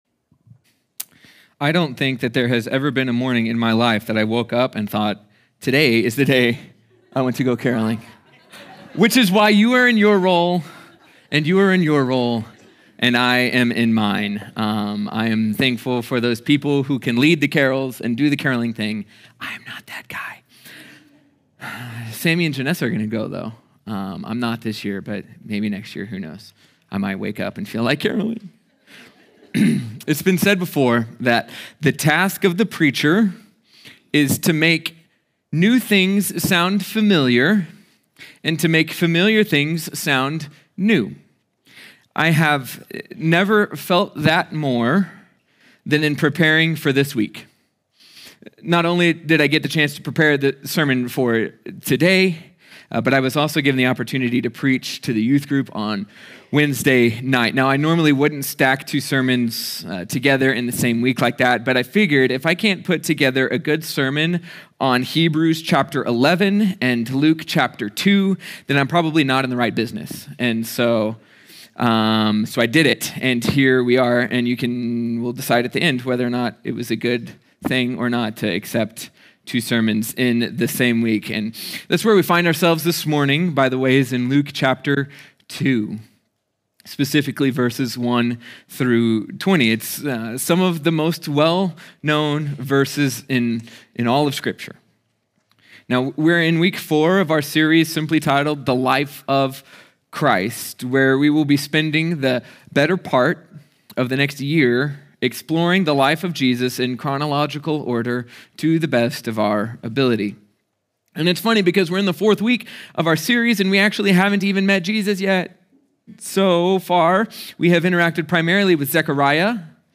sermon audio 1207.mp3